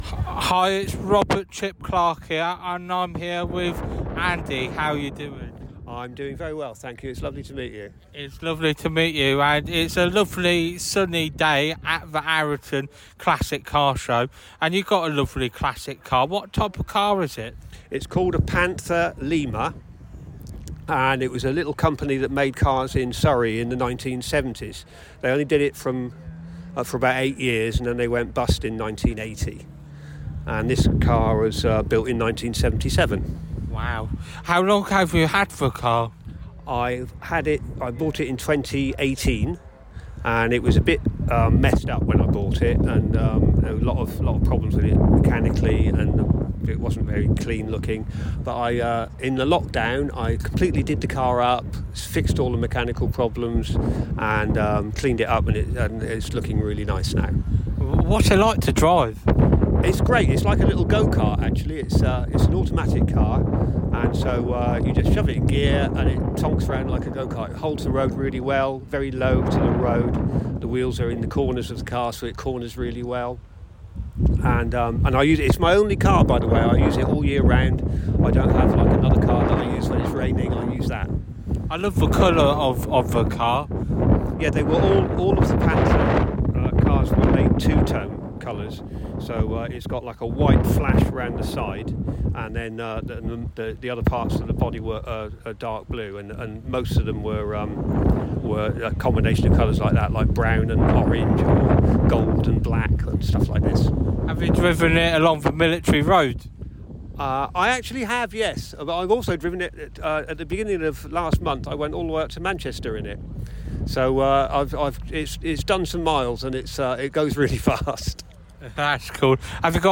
Arreton Car Show Interview 2024